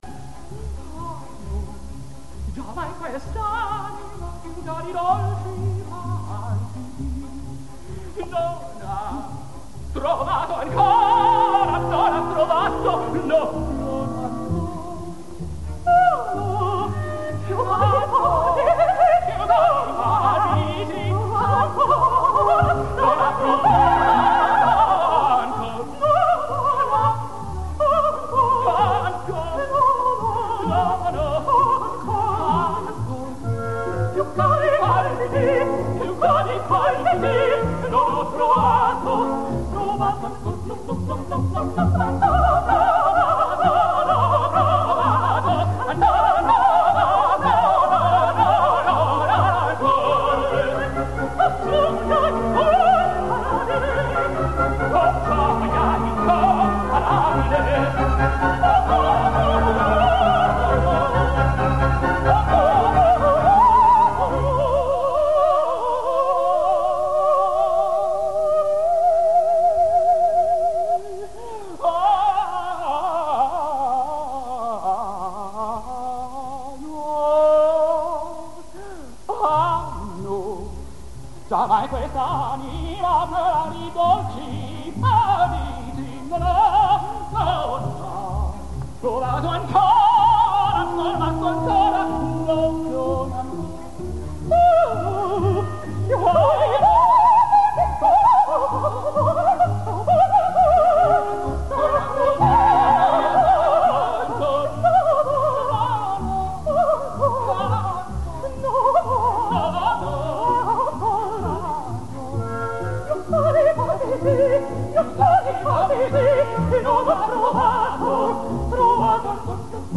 Rockwell Blake en mp3